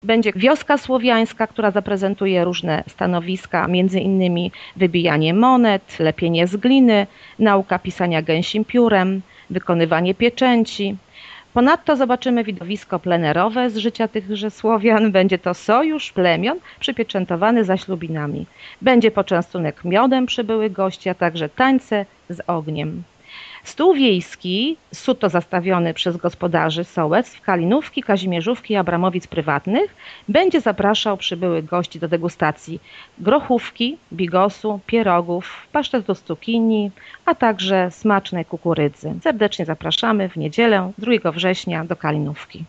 - Przeróżnych atrakcji dla uczestników w każdym wieku nie zabraknie - zapowiada zastępca wójta Gminy Głusk Urszula Paździor: